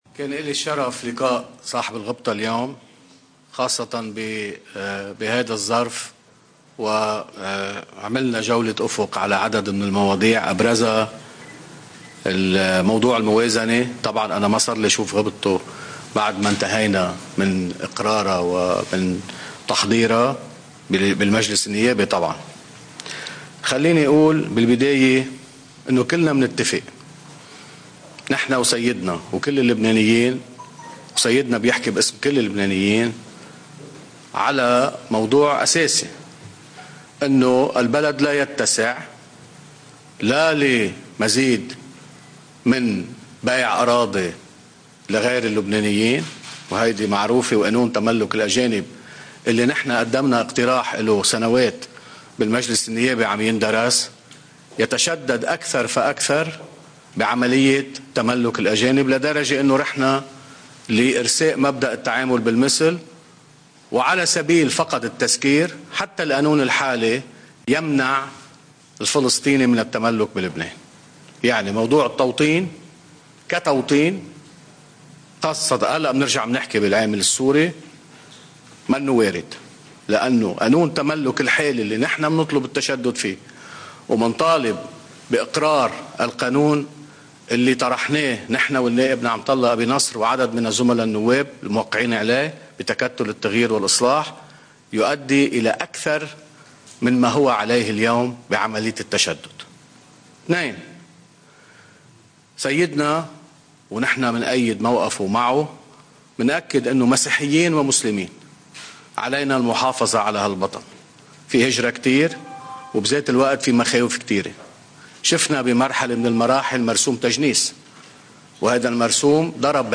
تصريح أمين سر تكتّل التغيير والإصلاح النائب ابراهيم كنعان من بكركي بعد لقائه البطريرك الراعي: (12 نيسان 2018)